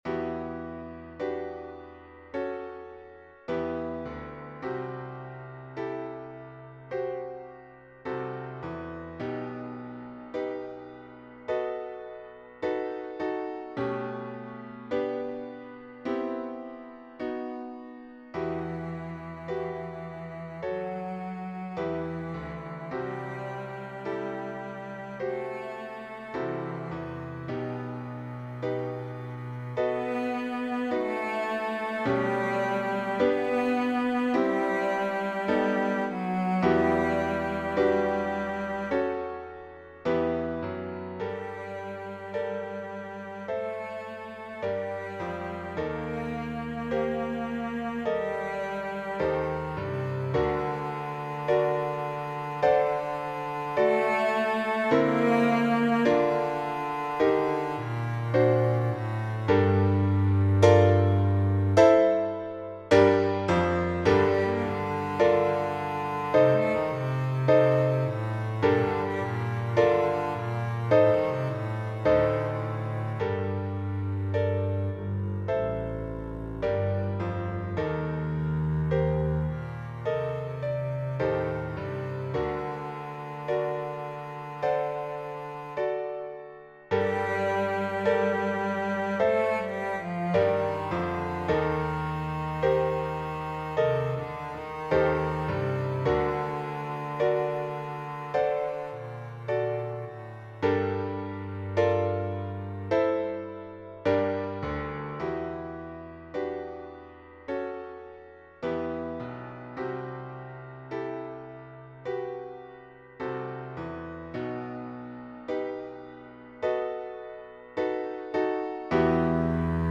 pour contrebasse et piano niveau cycle 2